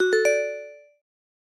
Звуки чекпоинта
На этой странице собраны звуки чекпоинтов — от четких электронных сигналов до игровых оповещений.
Звук перекрестного допроса